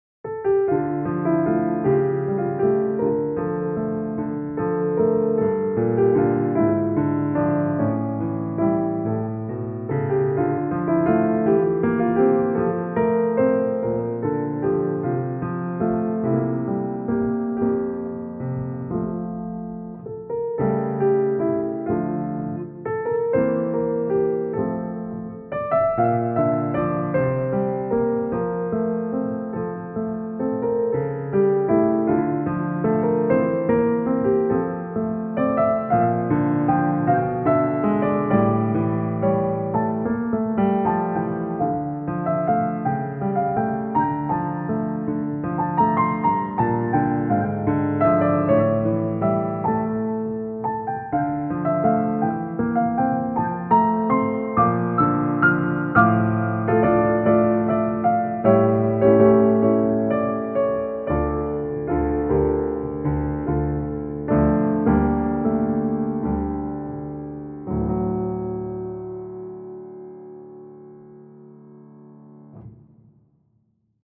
この課題は短調でも長調でも 良さそうですが、今回は短調  🄰 – 🄱 – 🄰の形。
ニ短調で始まり、同主調であるヘ長調のメロディーや和音を混ぜることで、曲が明るくなり変化します。
The task works in both major and minor, but here I shaped it as A − B − A in D minor.
It begins in D minor, shifts to the parallel key of F major for a brighter color, and then returns again.